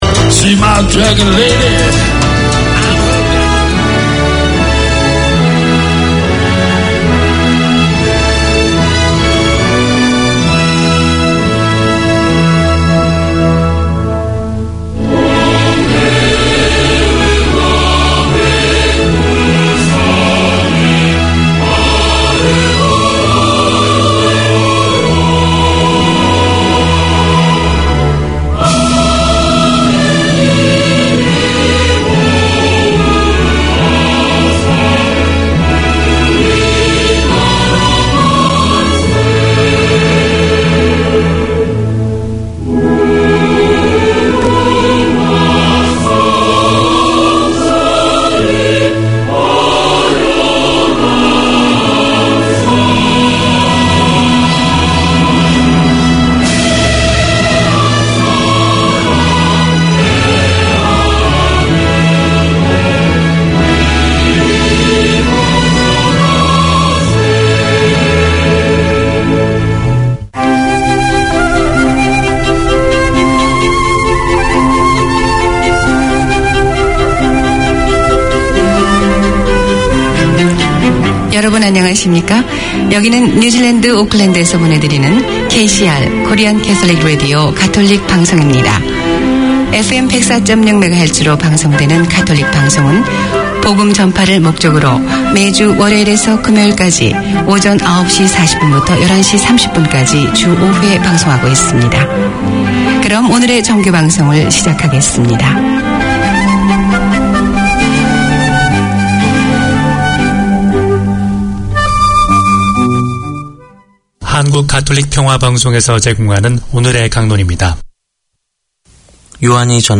Community magazine